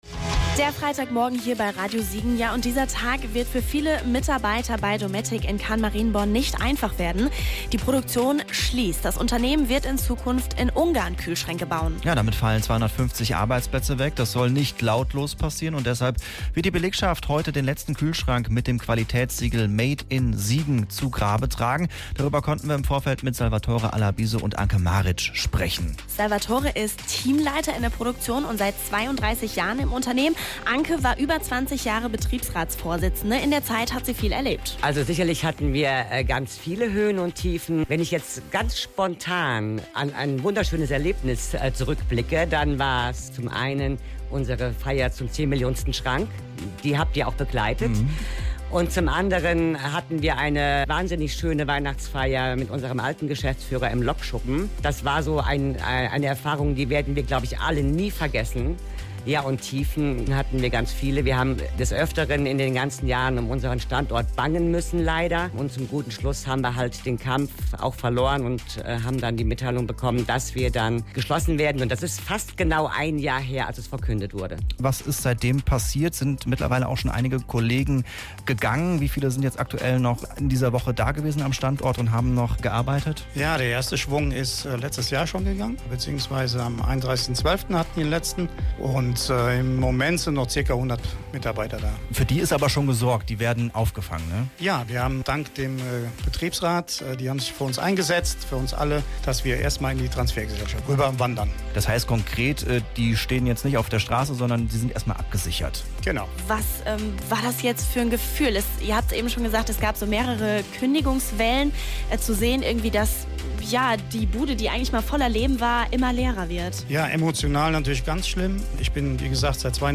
flor---mitschnitt-dometic-interview.mp3